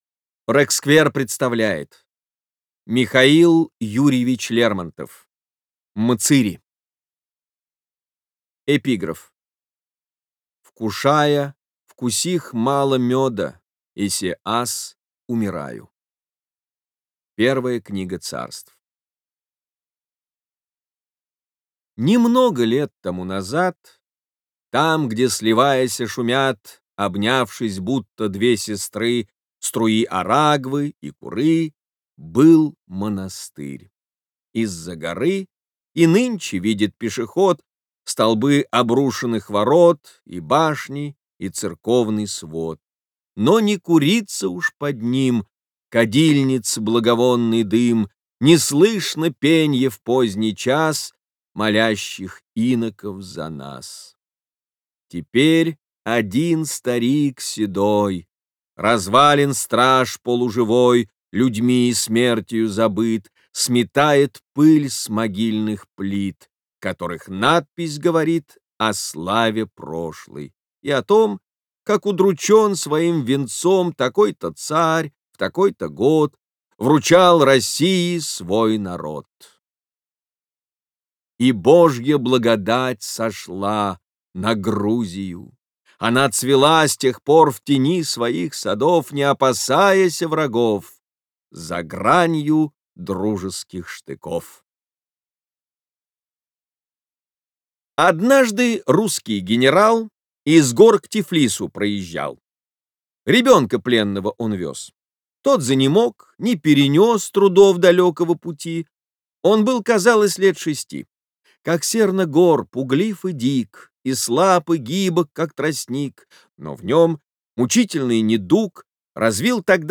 Бесплатная аудиокнига «Мцыри» от Рексквер.
Классическую литературу в озвучке «Рексквер» легко слушать и понимать благодаря профессиональной актерской игре и качественному звуку.